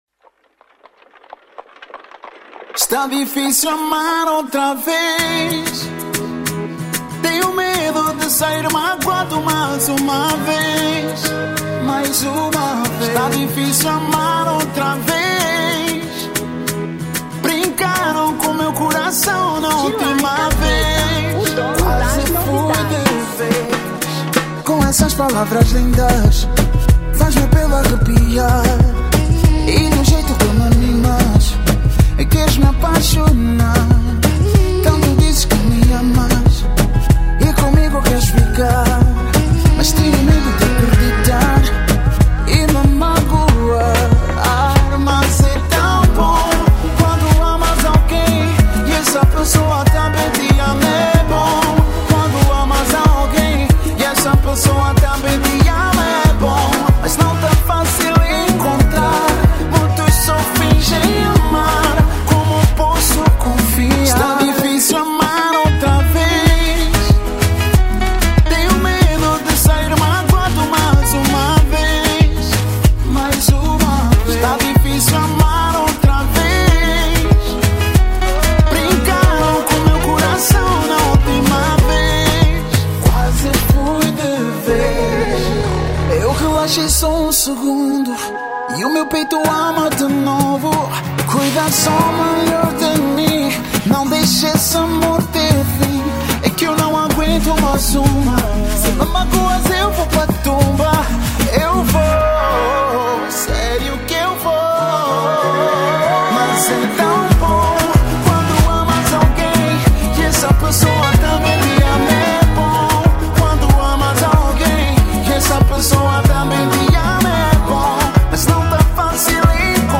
Reggaeton 2025